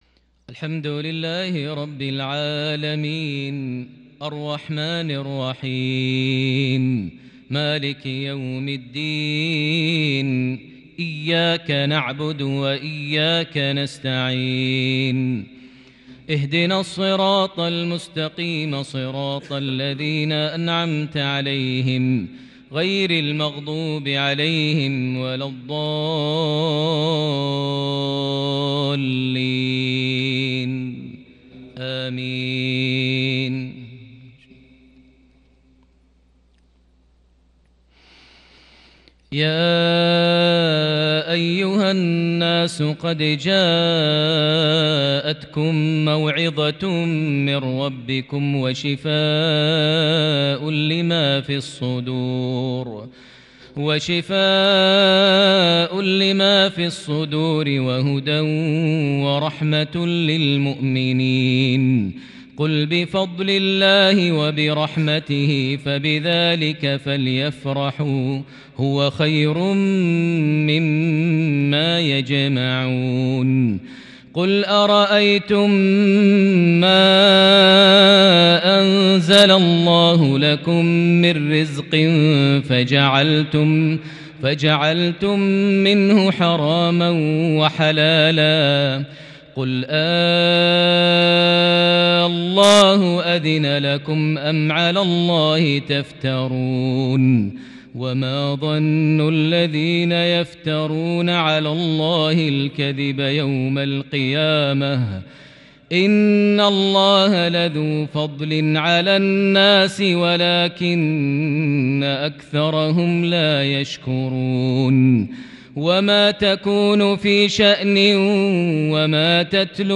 مغربية بديعة بالكرد من سورة يونس (57-64) | 23 جمادى الآخر 1442هـ > 1442 هـ > الفروض - تلاوات ماهر المعيقلي